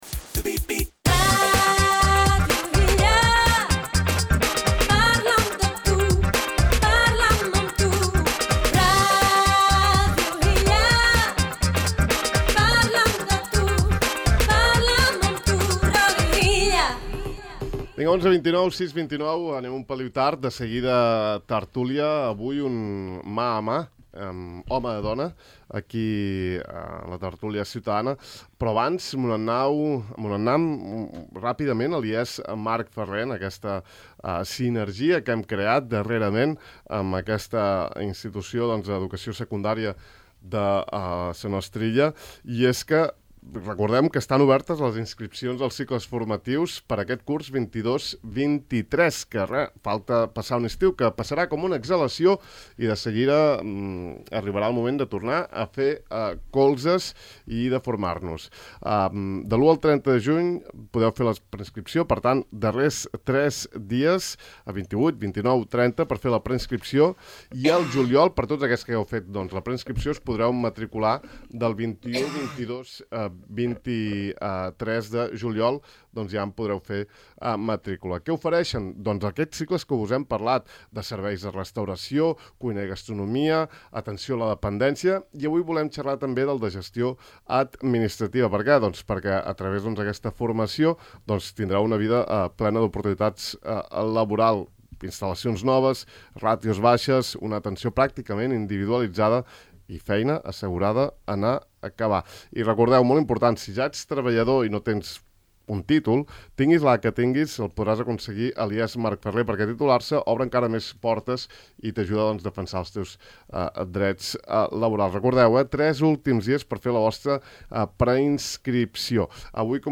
Escolta en aquest enllaç l’entrevista que els hi hem fet: